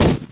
Bulletexplode Sound Effect
bulletexplode.mp3